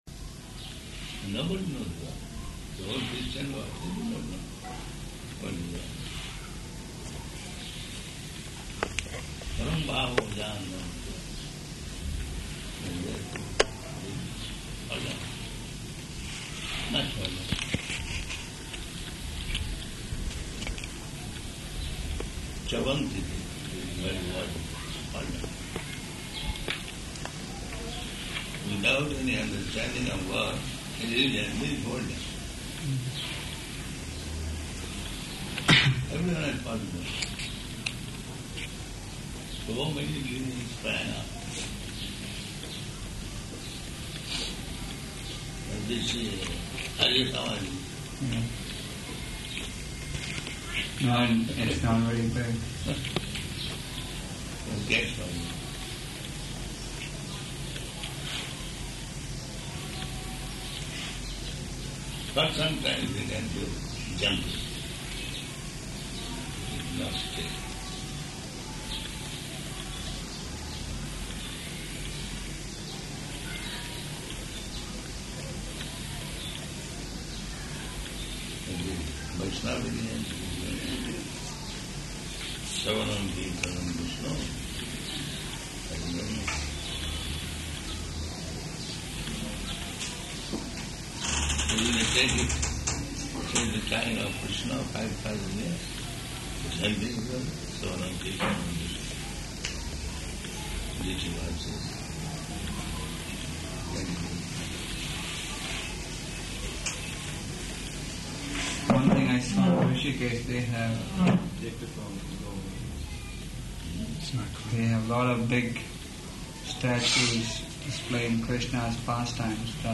Room Conversation
Room Conversation --:-- --:-- Type: Conversation Dated: May 8th 1977 Location: Rishikesh Audio file: 770508R3.HRI.mp3 Prabhupāda: Nobody knows God.